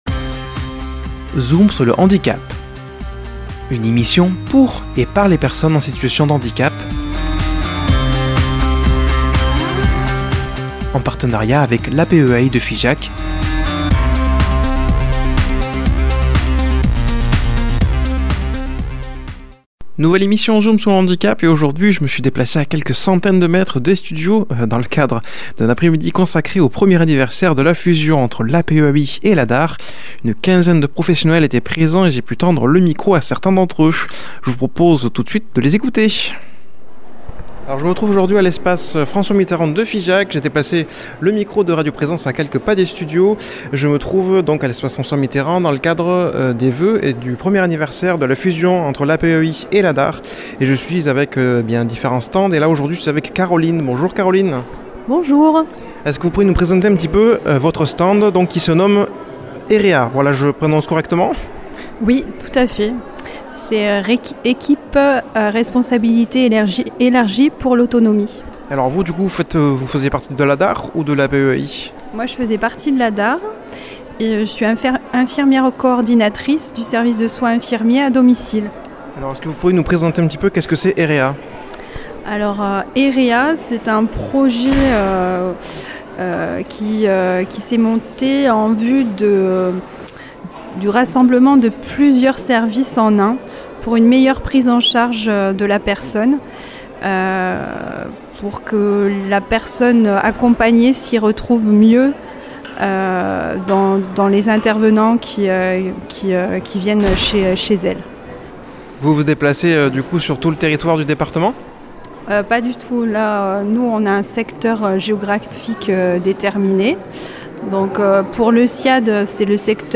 Une quinzaine de professionnels étaient présent et il a pu tendre le micro à certain d’entre eux. Un reportage en 2 parties dont voici la 1ère